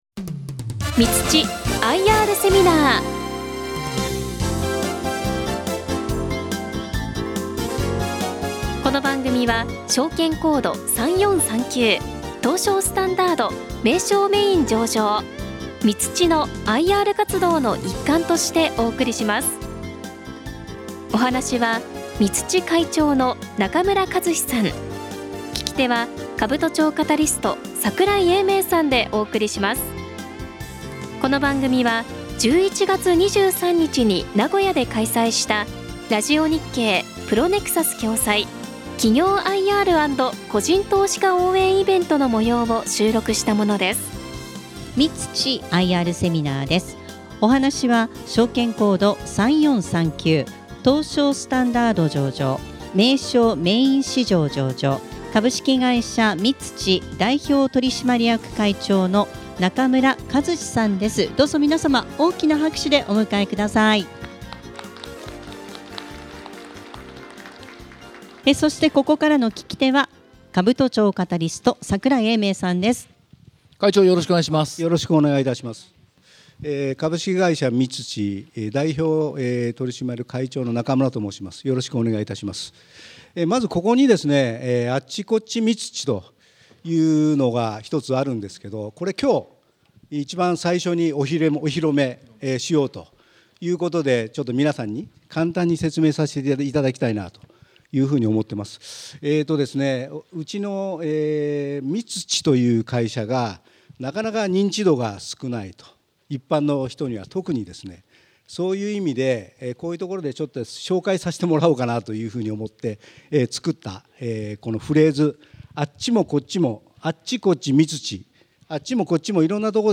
この番組は11月23日に名古屋で開催したIRセミナーの模様をダイジェストでお届けします。